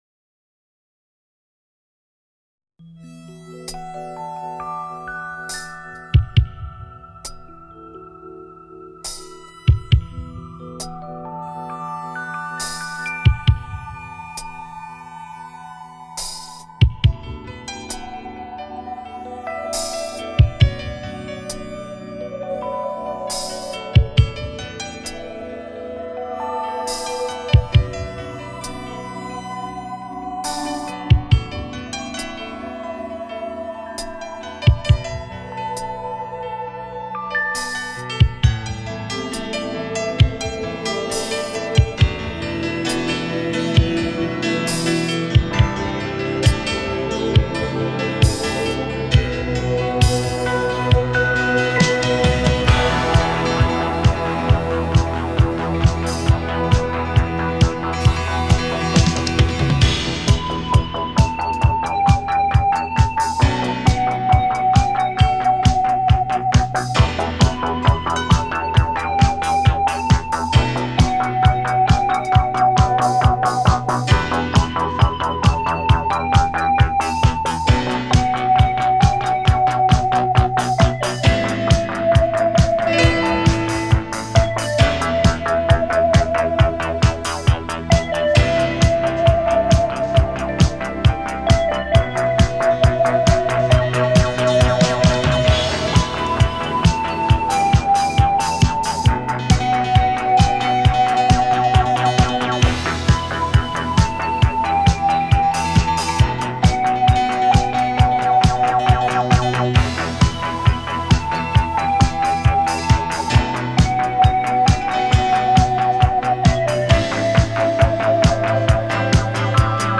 Жанр: Electronic; Битрэйт